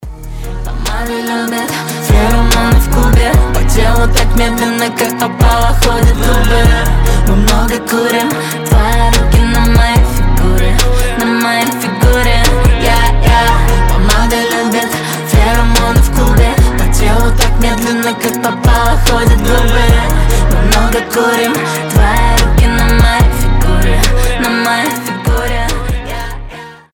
дуэт
чувственные